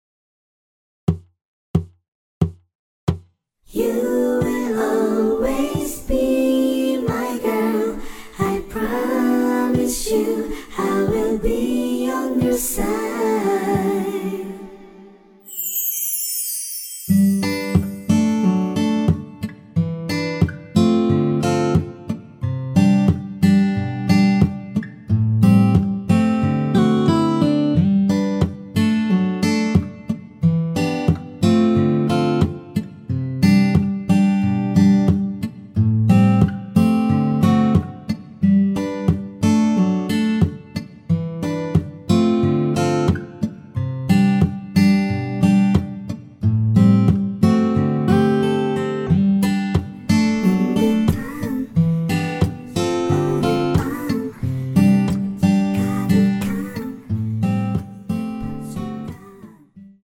노래 들어가기 쉽게 전주 1마디 넣었습니다.(미리듣기 확인)
원키에서(+3)올린 코러스 포함된 MR입니다.
앞부분30초, 뒷부분30초씩 편집해서 올려 드리고 있습니다.